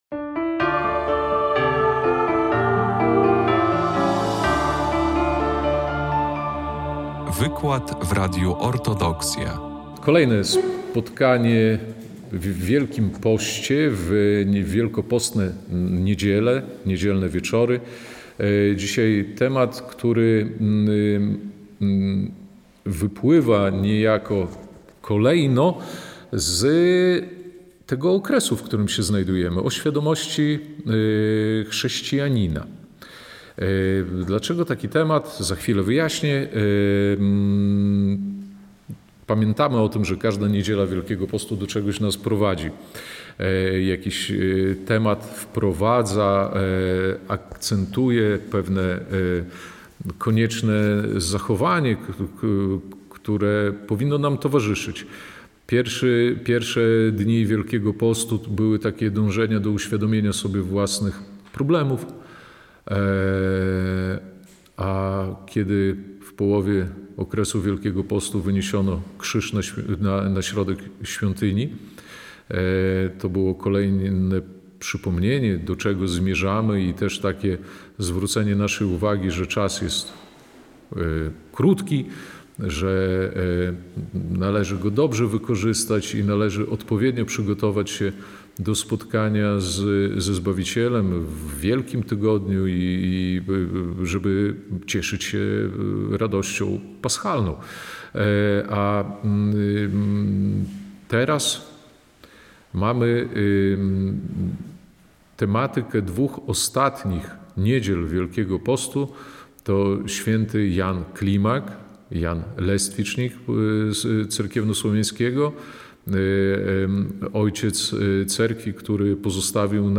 30 marca 2025 r. w parafii Zmartwychwstania Pańskiego w Białymstoku odbył się czwarty wykład w ramach Wielkopostnej Wszechnicy.